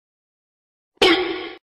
Chinese BOI sound effect
Tags: memes